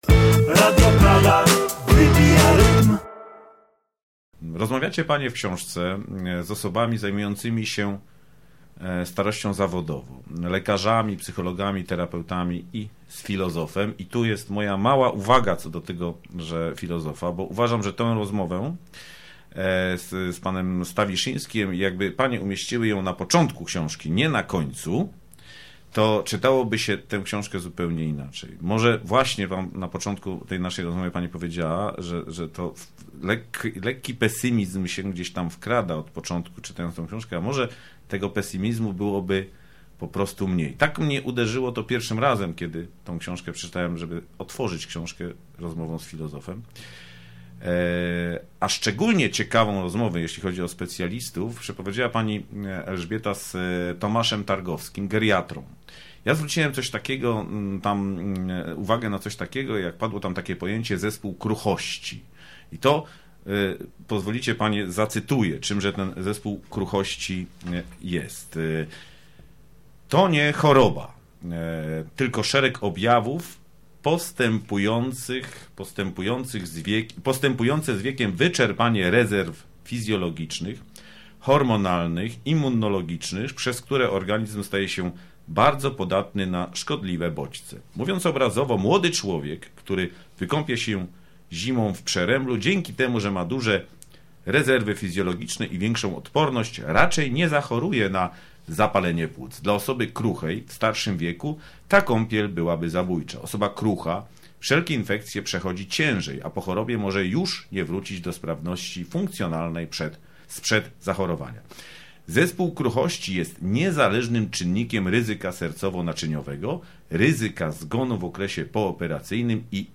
W drugiej części wywiadu